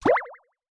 Cute water bubble.wav